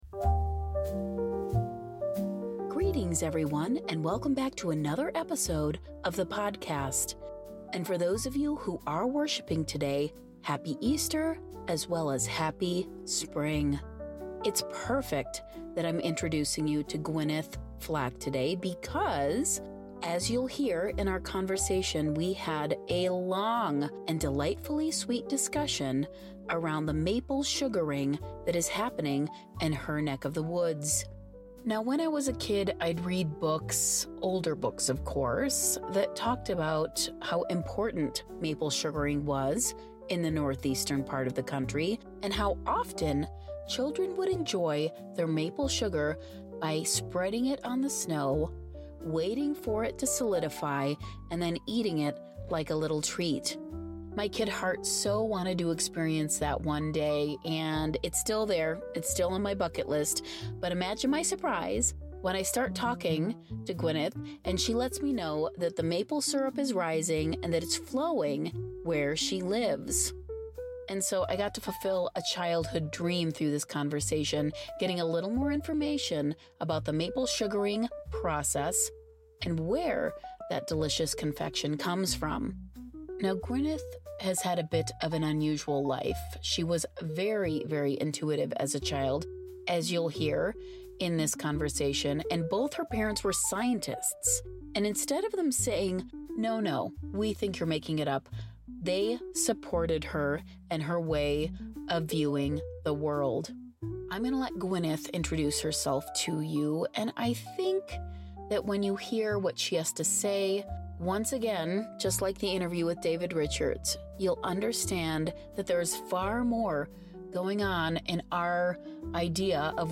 Byte Sized Blessings / Interview